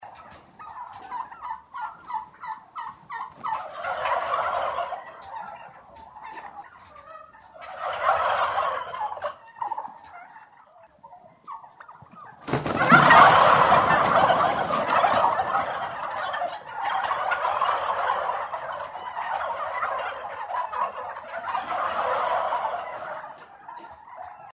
Звуки индюков